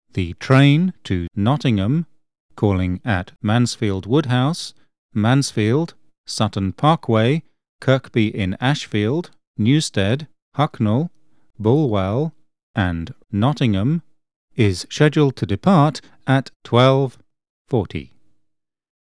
As well as displaying information on an ultra-bright daylight-viewable LCD display, our new generation of IDU systems are able to make audio announcements both relating to scheduled departure information, and relating to service disruption.
Nexus Alpha Ltd   Example of a scheduled departure message:
departure.wav